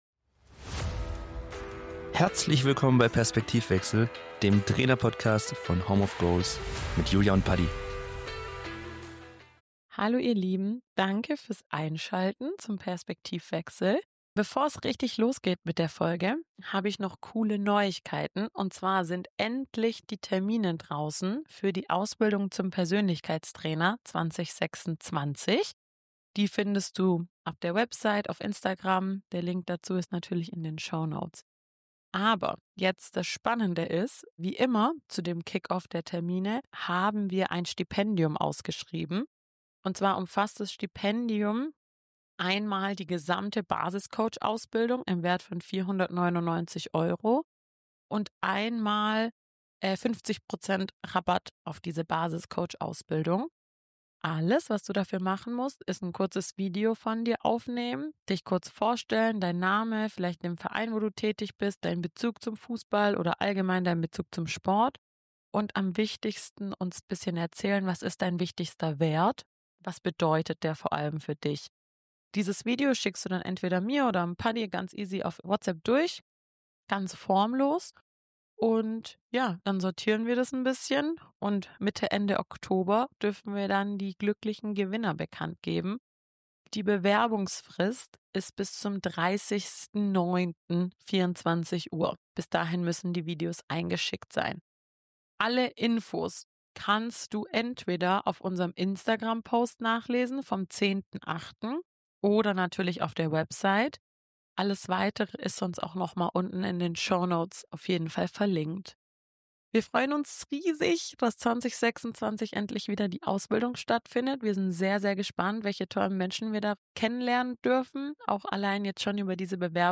Beschreibung vor 7 Monaten In dieser Folge ist der HSV Profi und unser Home of Goals Botschafter Daniel Elfadli bei uns im Gespräch. Es dreht sich heute alles um das Thema Dankbarkeit und Bodenständigkeit. Daniel gibt ehrliche Einblicke in seinen Weg, erzählt von prägenden Momenten seiner Karriere und verrät, wie er trotz sportlichem Erfolg geerdet bleibt.